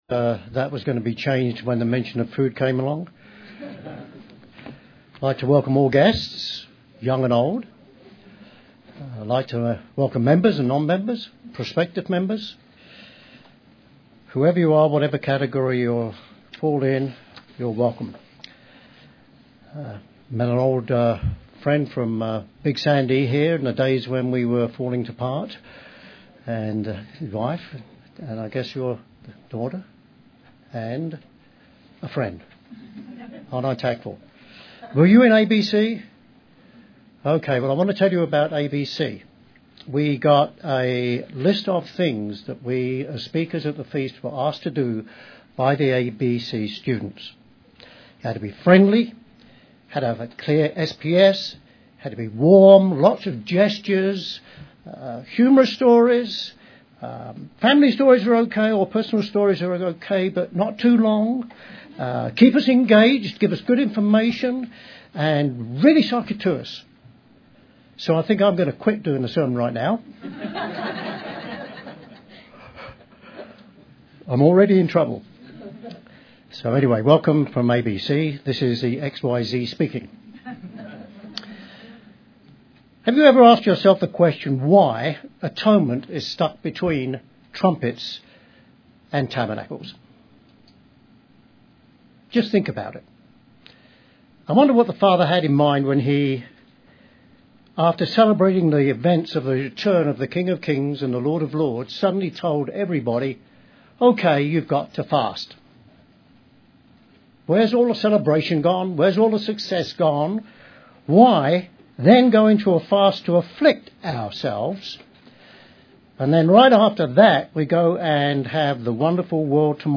Given in Colorado Springs, CO
UCG Sermon Studying the bible?